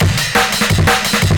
amen 1.wav